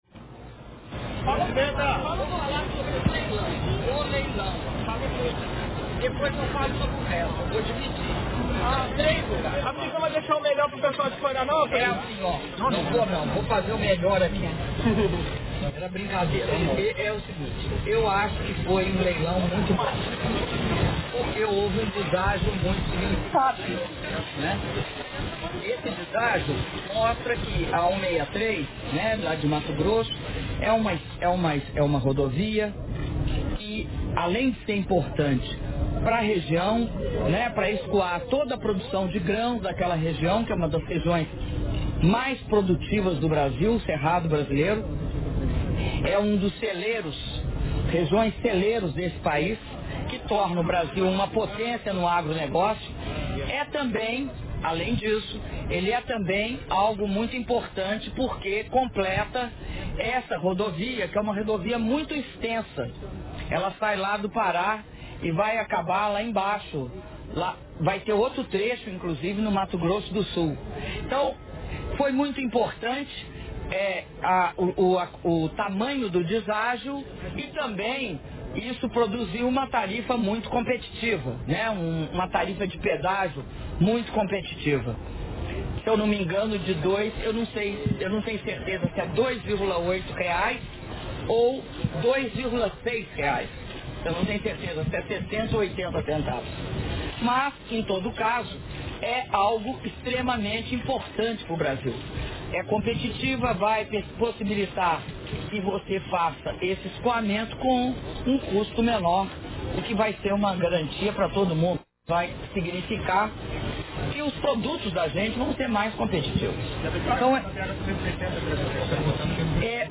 Áudio da entrevista concedida pela Presidenta da República, Dilma Rousseff, após cerimônia de inauguração do berço 201 e do Porto de São Francisco do Sul - São Francisco do Sul (SC)